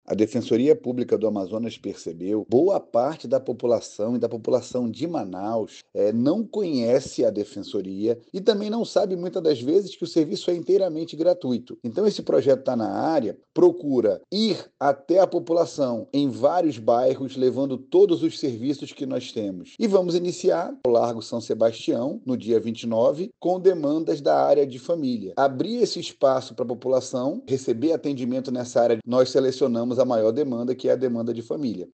O Defensor Público Geral do Amazonas, Rafael Barbosa, destaca que o novo projeto da DPE-AM foi desenvolvido de forma colaborativa, com a participação ativa dos defensores e suas experiências diárias.